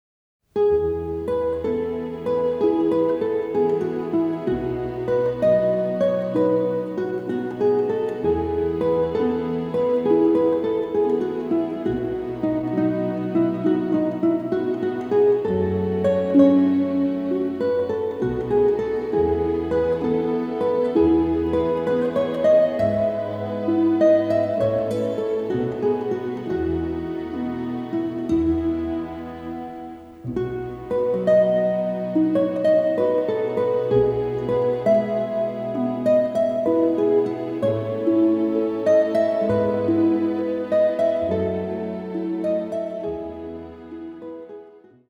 a delicate and poetic score
Remastered from the scoring session tapes